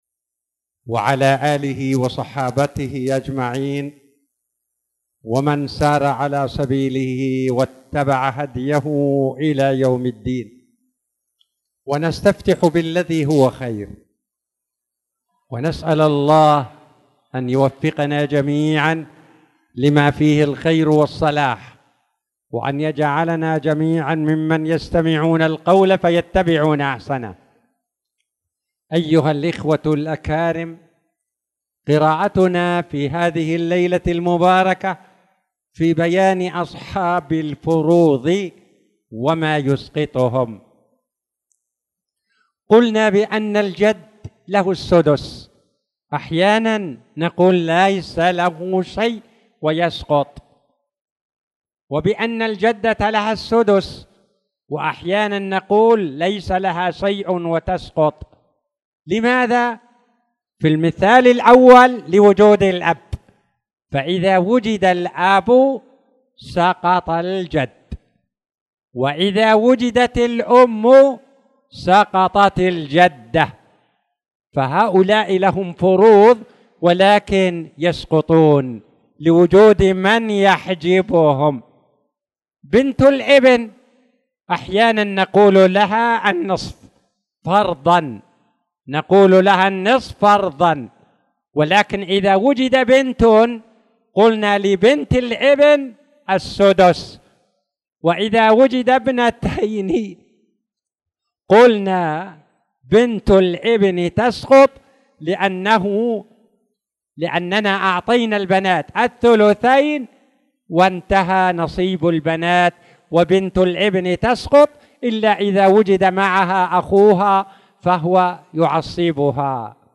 تاريخ النشر ١٤ شوال ١٤٣٧ هـ المكان: المسجد الحرام الشيخ